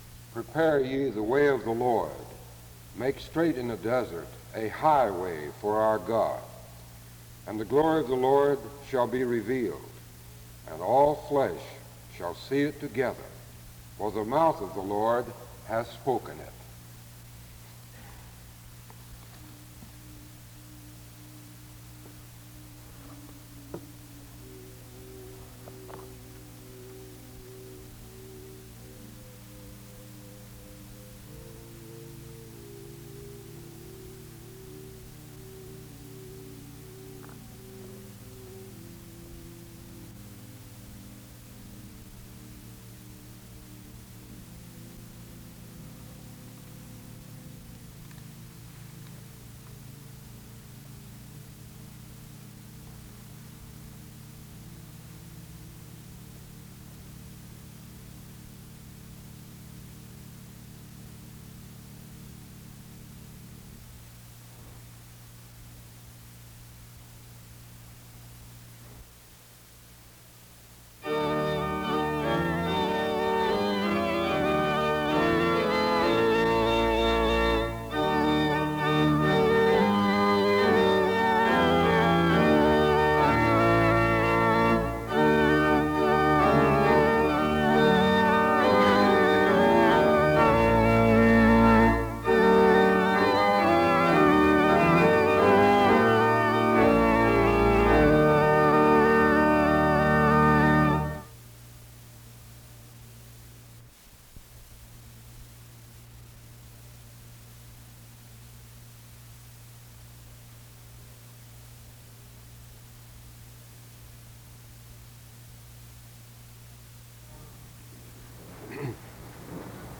The service begins with music from 0:00-1:41. A responsive reading is read from 2:05-3:39. A prayer is offered from 3:48-5:56. An introduction to the speaker is given from 6:02-7:20. Music plays from 7:29-11:17. Dr. Nida preaches from 11:19-57:33. Nida promotes how valuable the task of a preacher is.